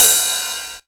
TR909RIDE.wav